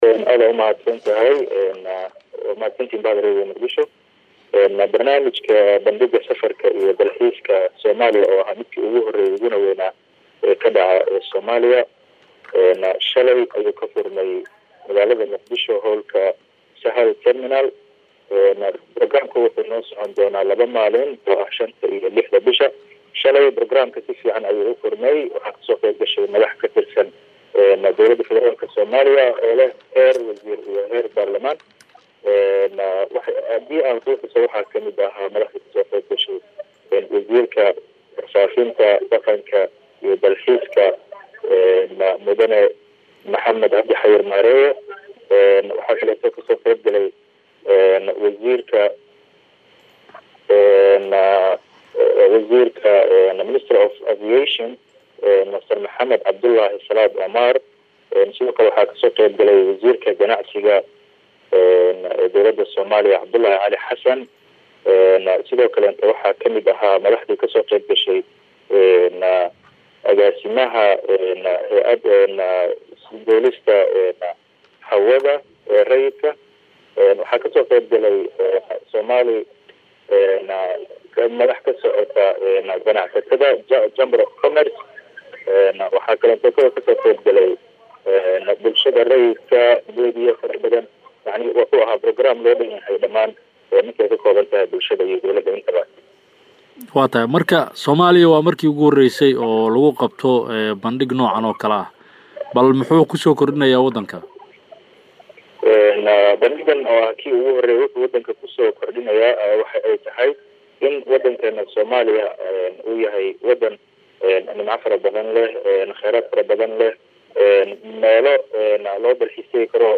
Wareysi ku saabsan Bandhiga Safarka iyo Dalxiiska Soomaaliya “Dhageyso”